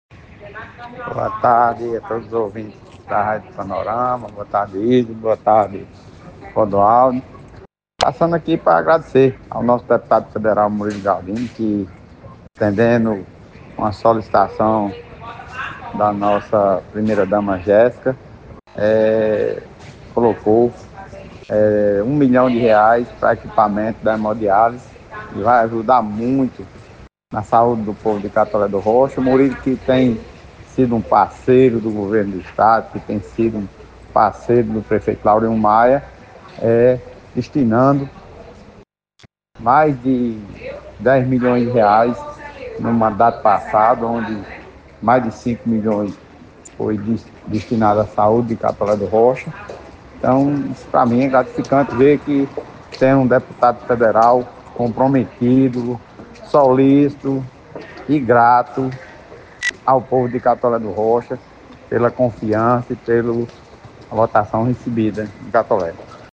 Ouça o Prefeito Laurinho Maia:
Em sua participação na tarde desta terça-feira, dia 03 de junho, ao Jornal Panorama Notícias, da Rádio Clube FM 96,7, o prefeito destacou a importância do investimento para a saúde regional:
Prefeito-Laurinho-Maia.mp3